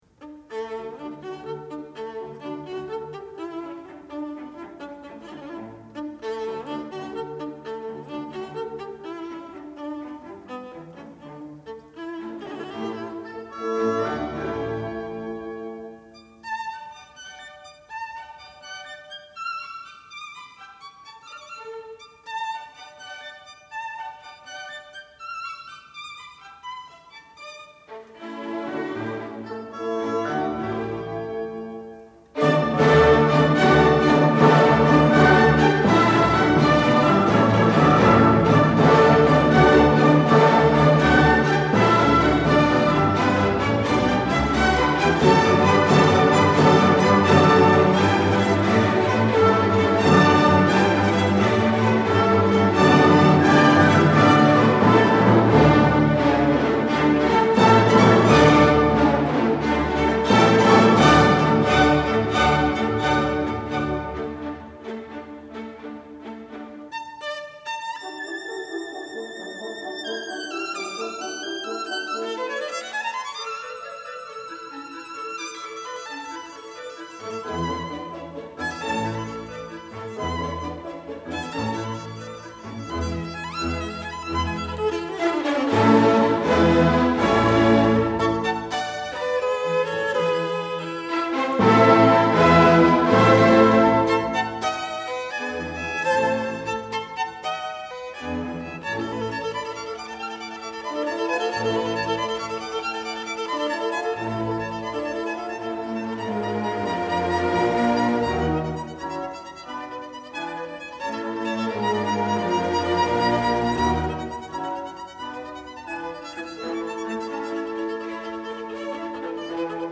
Rondo.mp3